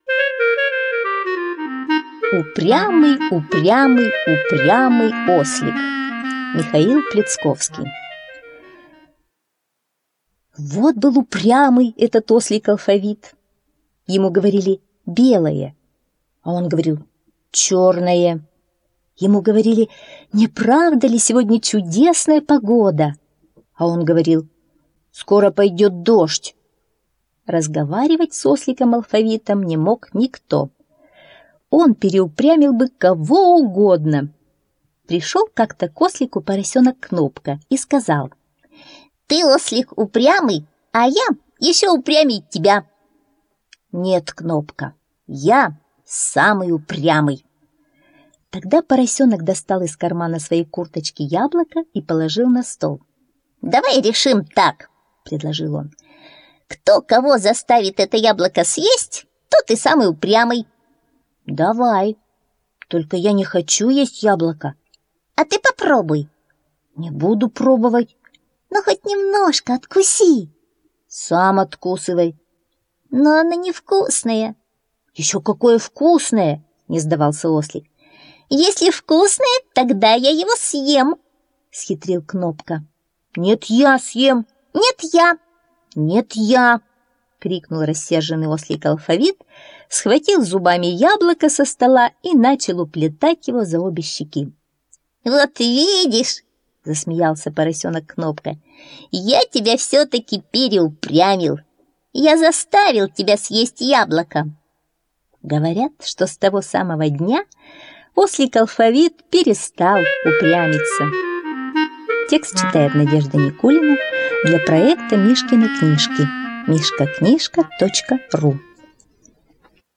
Упрямый, упрямый, упрямый ослик - аудиосказка - слушать